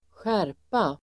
Uttal: [²sj'är:pa]